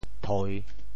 “代”字用潮州话怎么说？
thoi3.mp3